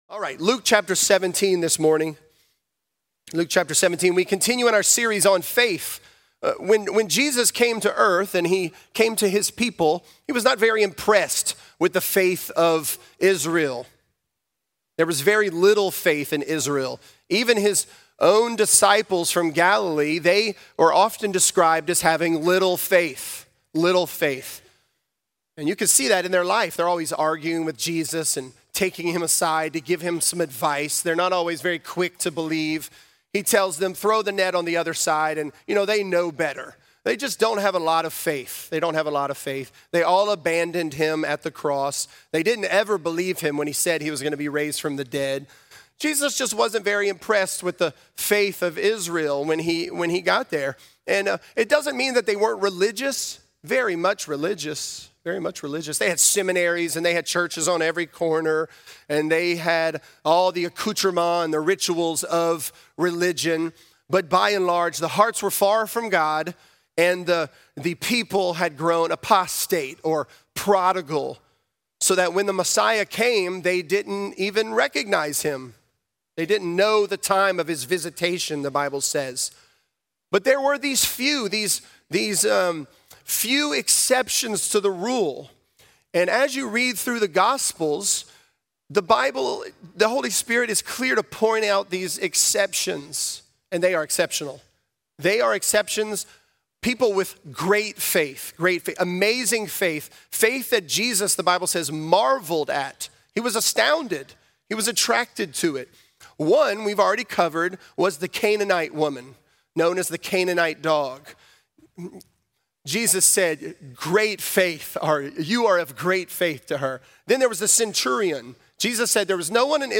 Mountain Moving Faith: The Samaritan Leper | Lafayette - Sermon (Luke 17)